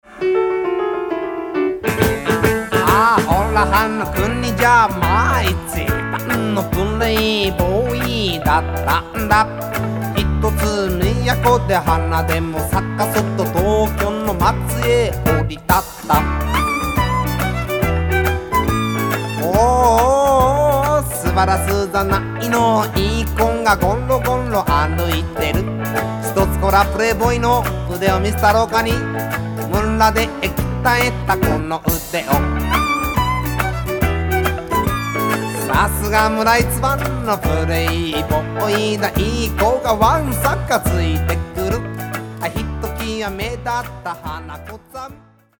ジャンル：フォーク